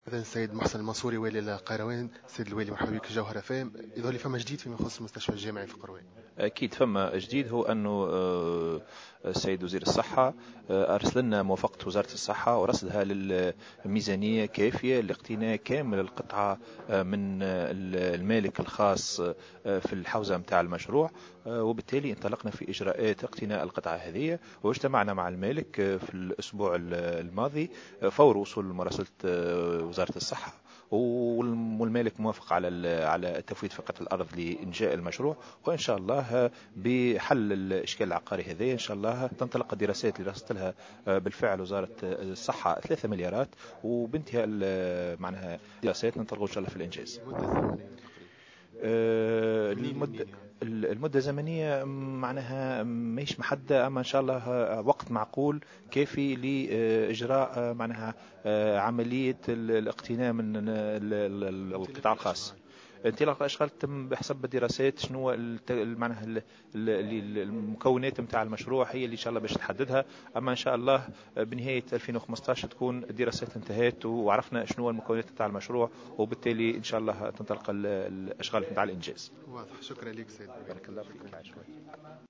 Mohsen Mansouri, gouverneur de Kairouan a indiqué ce jeudi 11 décembre 2014 dans une intervention au micro de Jawhara FM, que le ministère de la santé publique a garanti l’octroi de 3 millions de dinars comme budget pour la construction d’un nouveau dispensaire dans le gouvernorat.